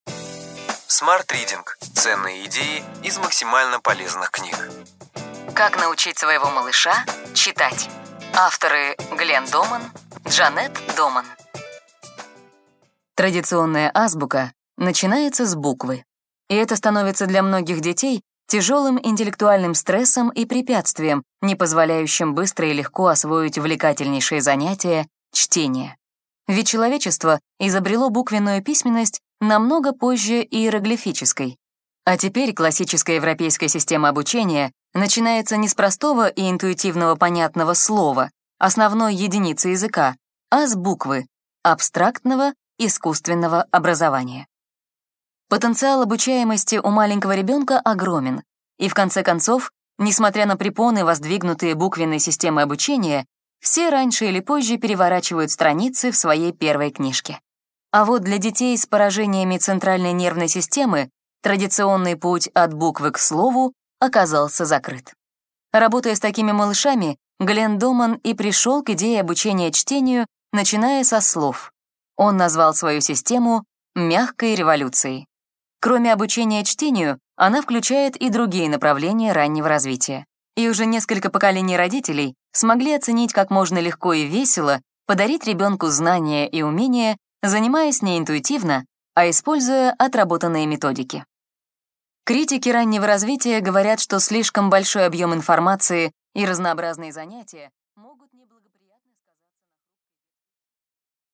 Аудиокнига Ключевые идеи книги: Как научить своего малыша читать.